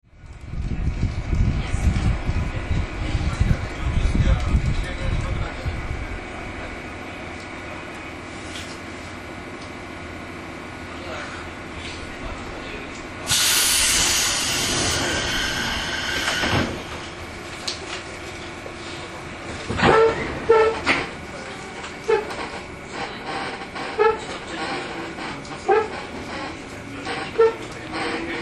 走行音
TK04 211系 湯河原→真鶴 3:42 9/10 上の続きです。